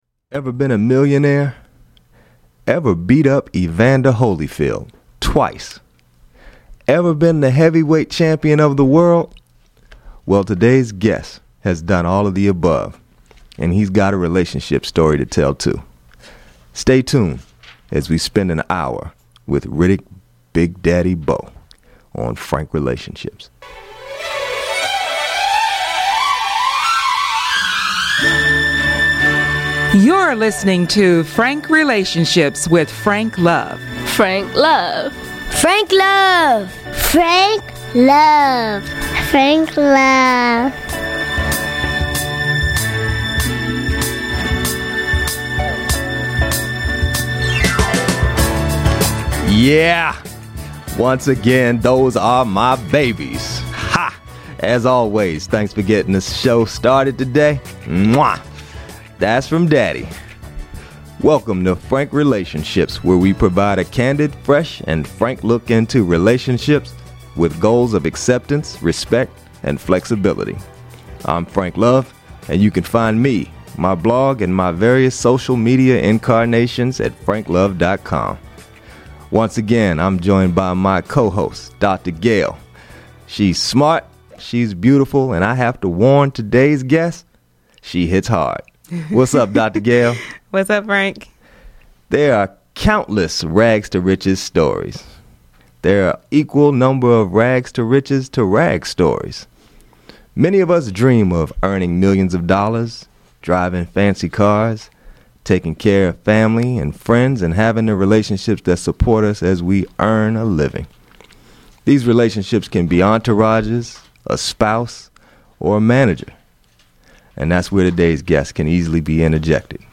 FORMER TWO-TIME HEAVYWEIGHT CHAMPION Guests: Riddick Bowe Date